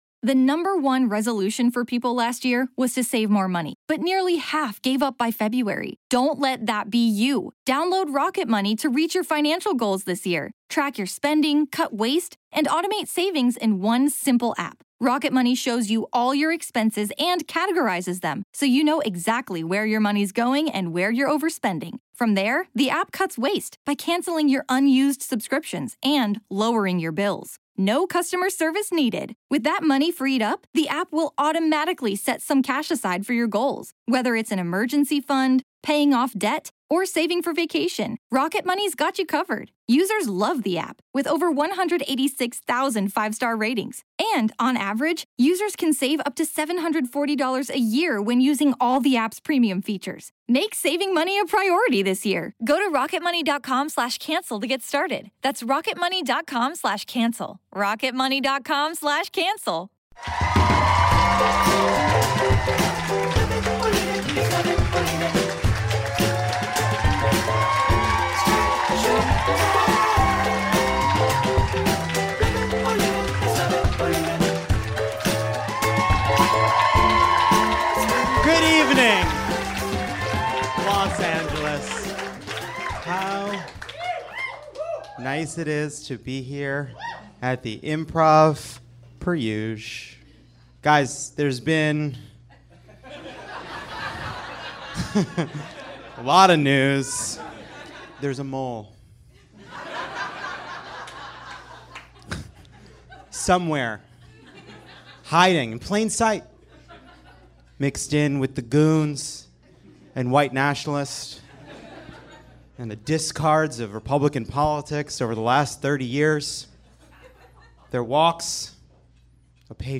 We are joined by an all-star panel